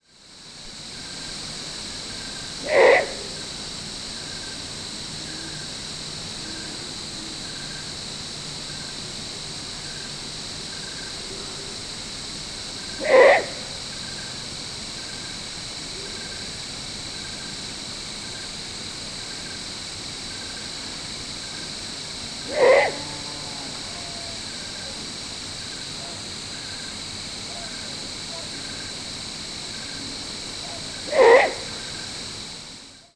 The variable begging call of juvenile Great Horned Owl can be similar but is usually more upward-arched or wavering, giving it a less monotone sound.
Great Horned Owl juvenile calling sequences:
Perched bird.